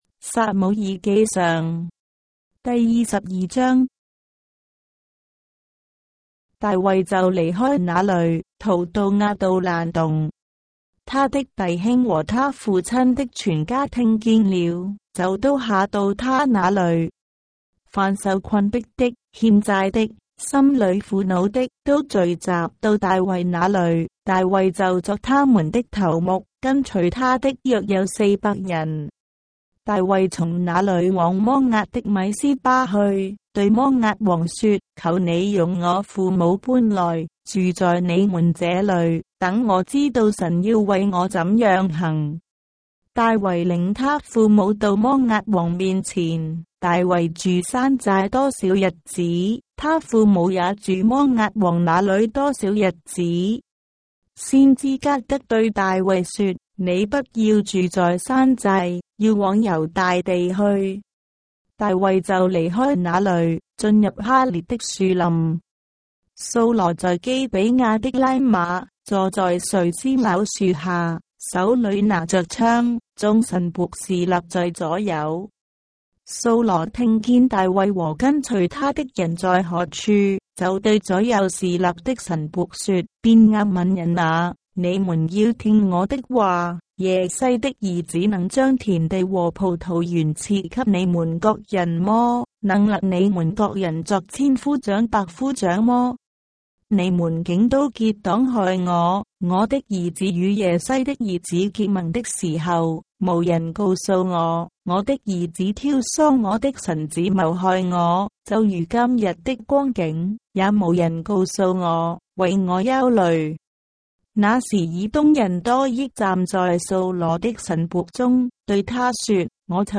章的聖經在中國的語言，音頻旁白- 1 Samuel, chapter 22 of the Holy Bible in Traditional Chinese